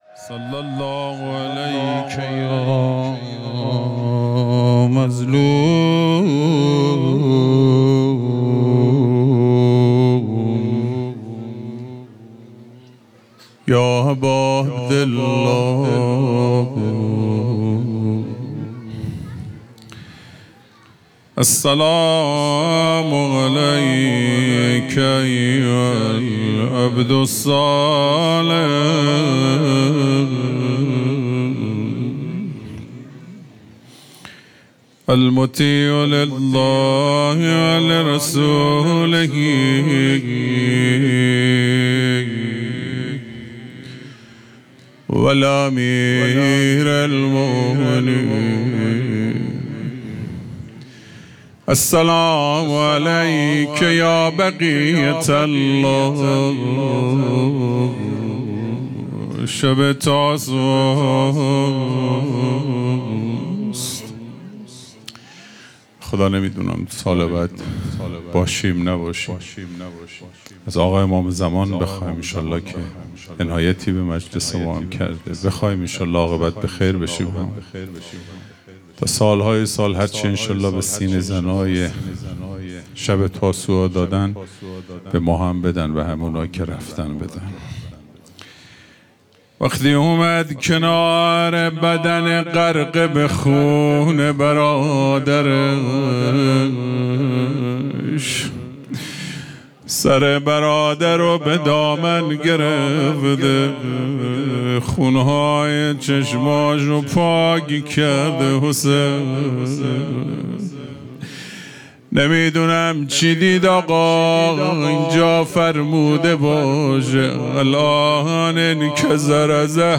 خیمه گاه - هیئت محبان الحسین علیه السلام مسگرآباد - روضه پایانی